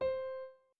01_院长房间_钢琴_11.wav